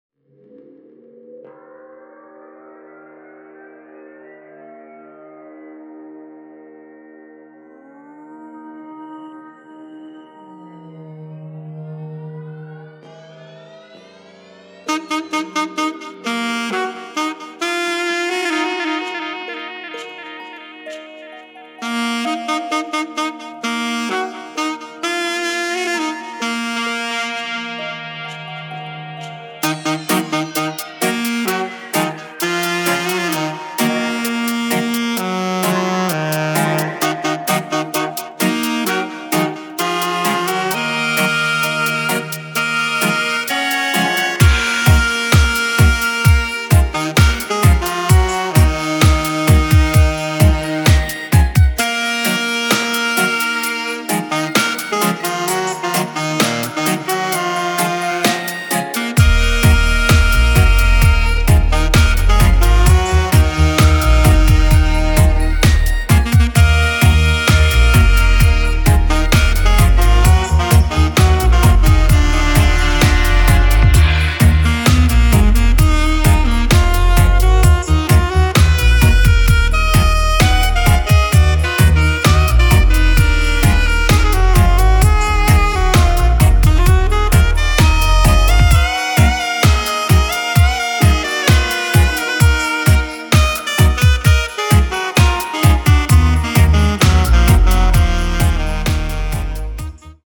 horns version